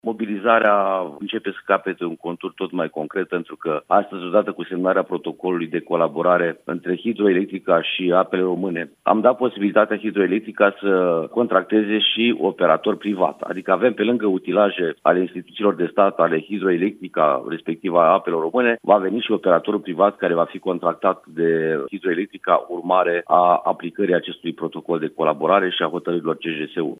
O amplă acţiune de curăţare a lacului Izvorul Muntelui a început încă de ieri, după cum a mai declarat prefectul de Neamț, Adrian Bourceanu: